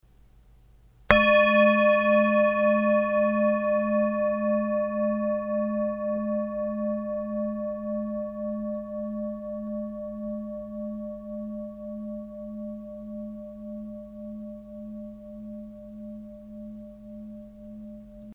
Indische Bengalen Klangschale - HERZSCHALE
Gewicht: 694 g
Durchmesser: 16,9 cm
Grundton: 215,46 Hz
1. Oberton: 602,97 Hz